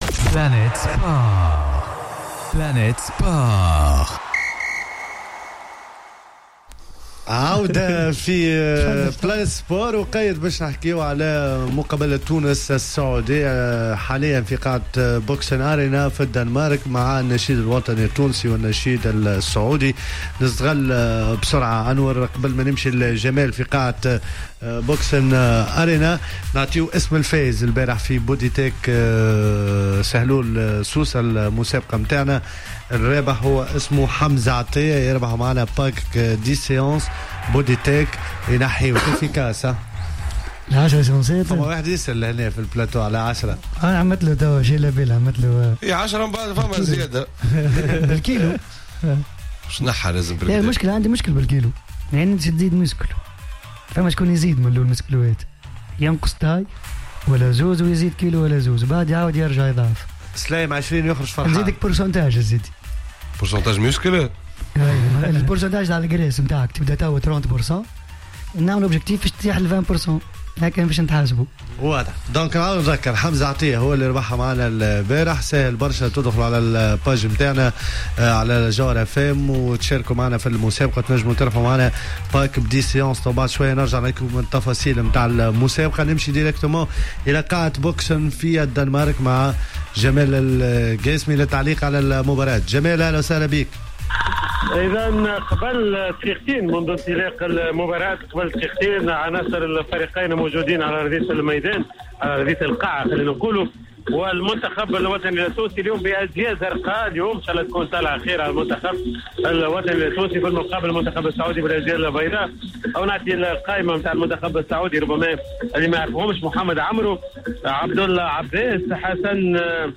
متابعة مباشرة لمقابلة تونس و السعودية في مونديال كرة اليد